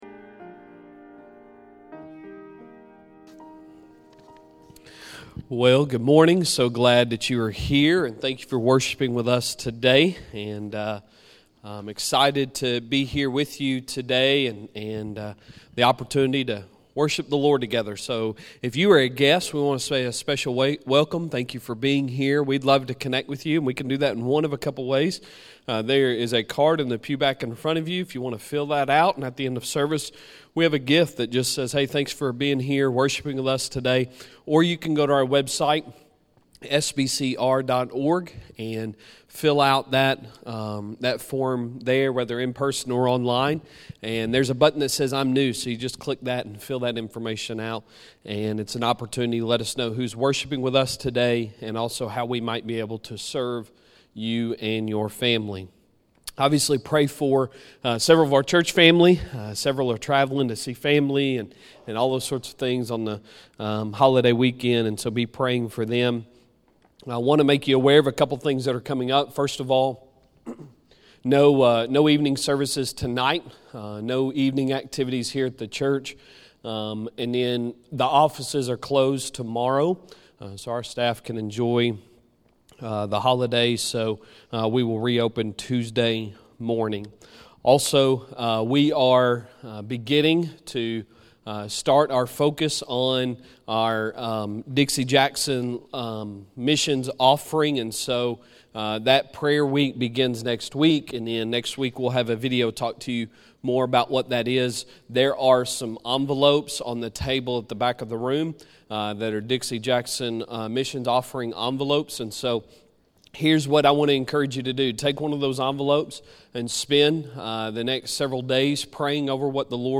Sunday Sermon September 4, 2022